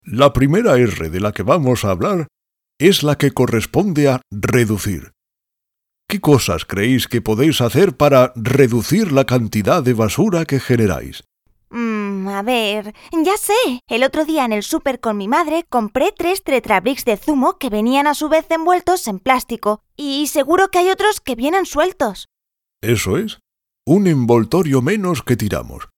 Tengo mi propio estudio de grabación, con microfonía Rode y ordenadores Apple.
kastilisch
Sprechprobe: Industrie (Muttersprache):